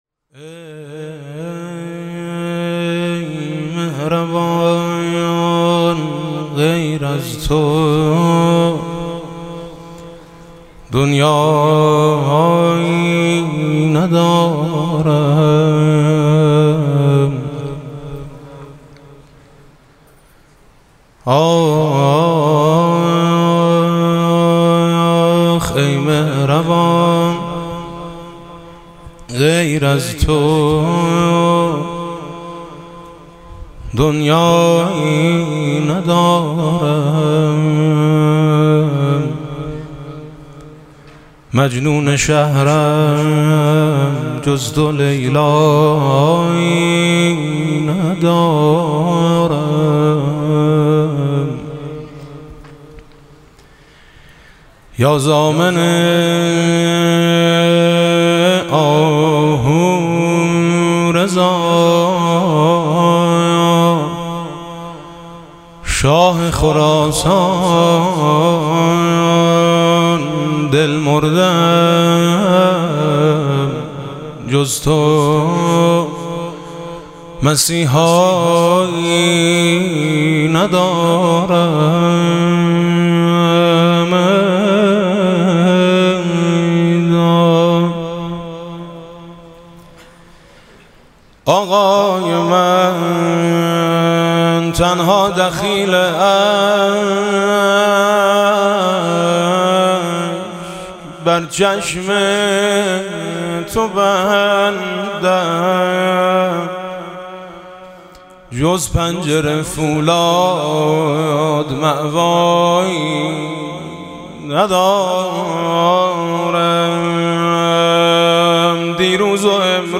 «میلاد امام رضا 1392» مدح: ای مهربان غیر از تو دنیایی ندارم